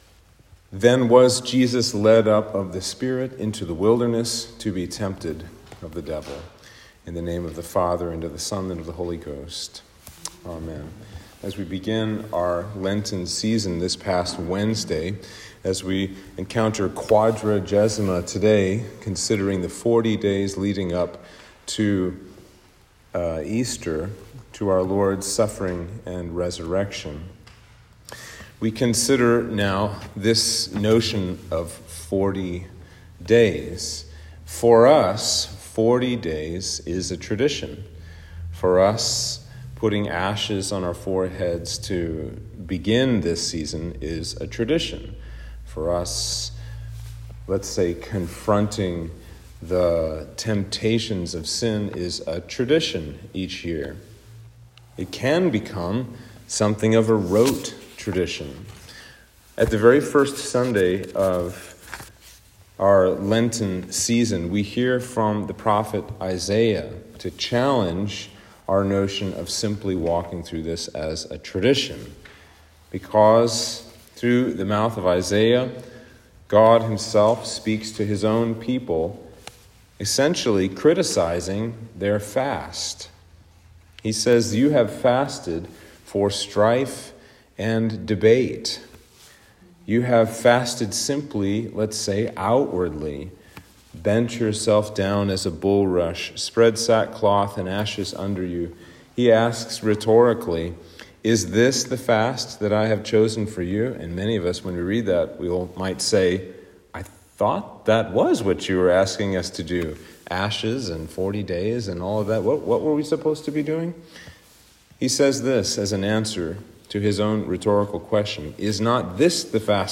Sermon for Lent 1 - 2025